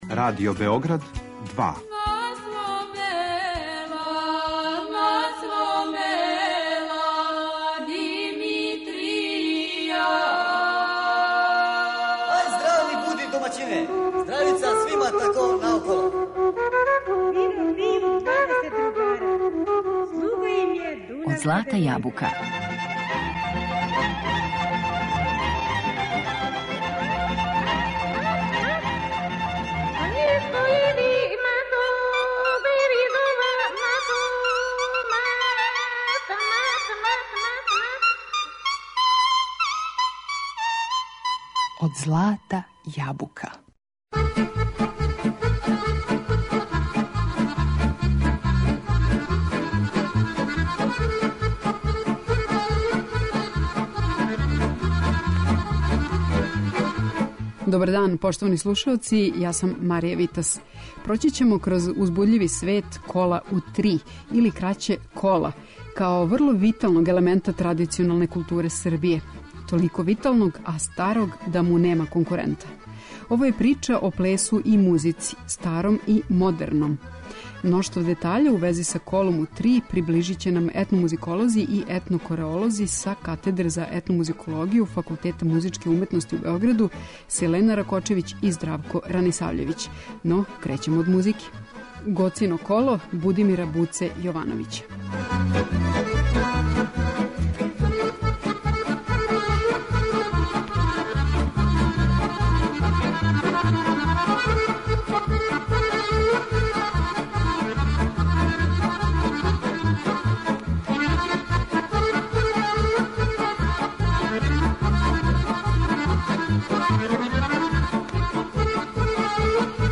Током емисије слушаћемо музику за кола, старија и новија, која и данас живе у пракси.